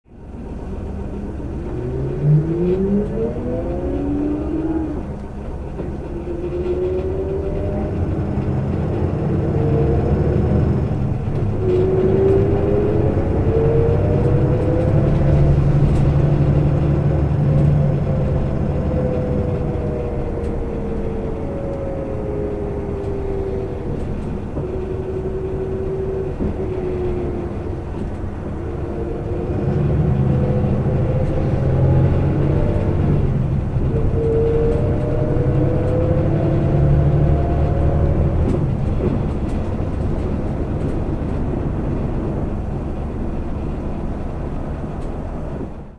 発進時はモーター動力で駆動し、加速時はディーゼルエンジンをモーターがアシスト。
走行音(46秒・902KB)